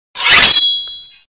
Swords, Knives & Daggers ringtone free download
Sound Effects